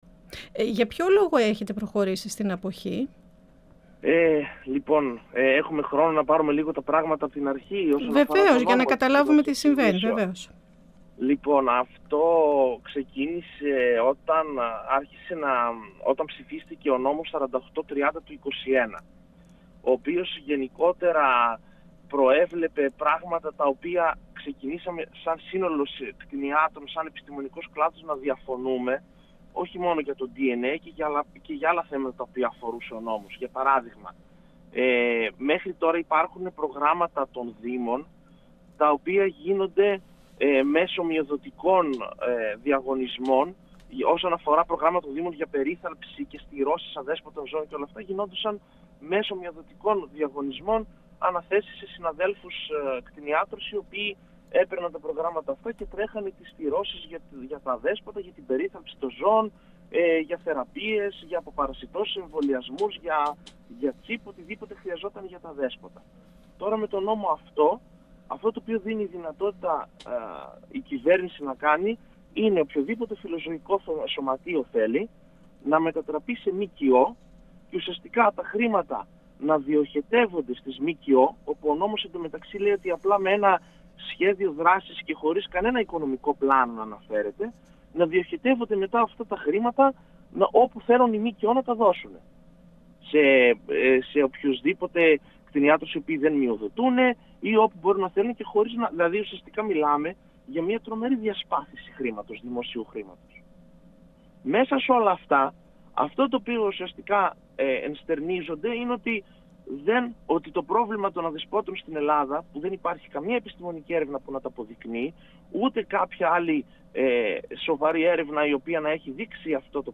Οι ιδιοκτήτες φοβούνται τα πρόστιμα και πάνε σε κτηνιάτρους και σημάνουν τα ζωάκια τους». 102FM Αιθουσα Συνταξης Συνεντεύξεις ΕΡΤ3